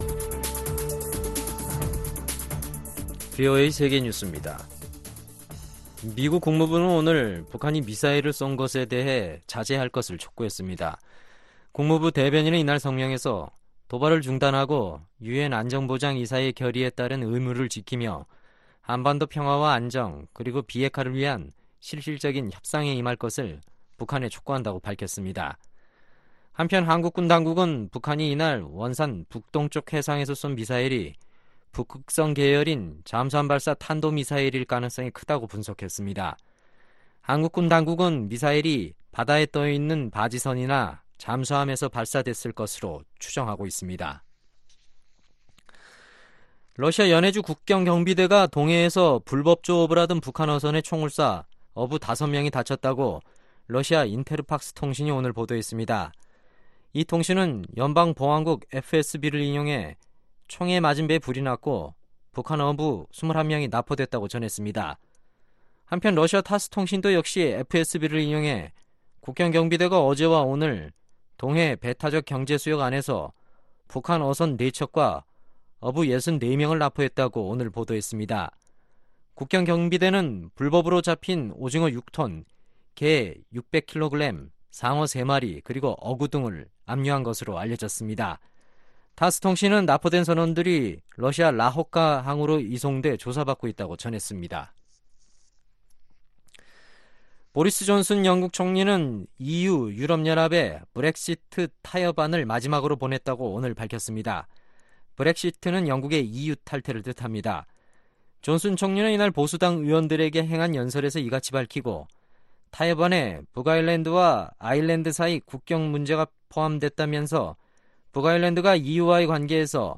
VOA 한국어 간판 뉴스 프로그램 '뉴스 투데이', 2019년 10월 2일 3부 방송입니다. 북한이 미국과 오는 5일 실무협상을 진행하기로 했다고 발표했습니다. 미국과의 실무협상을 앞둔 북한이 잠수함발사 탄도미사일로 추정되는 발사체를 쏘았습니다. 미국은 북한과 실무협상에 돌입할 예정인 가운데, 국방부 고위 관리가 중국에 대북 경제제재를 제대로 이행해야 한다고 촉구했습니다.